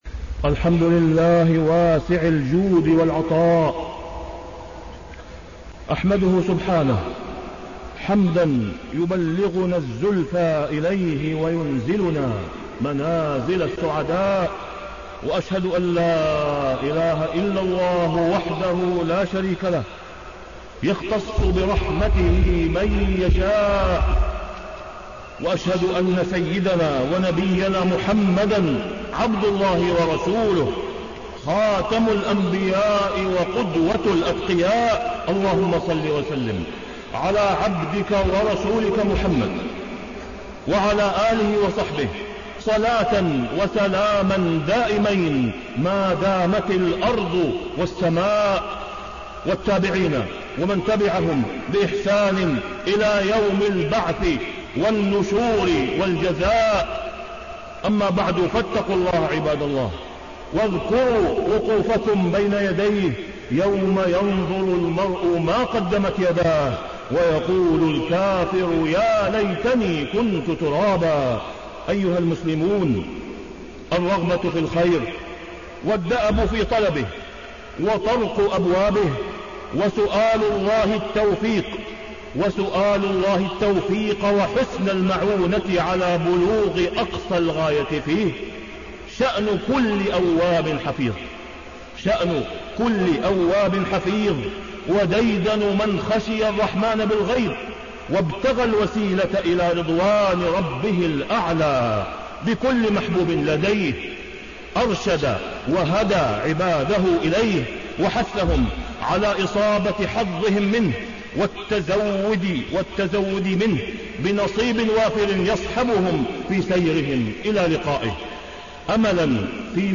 تاريخ النشر ٧ محرم ١٤٣٣ هـ المكان: المسجد الحرام الشيخ: فضيلة الشيخ د. أسامة بن عبدالله خياط فضيلة الشيخ د. أسامة بن عبدالله خياط الرغبة في الخير وتعدد أسبابه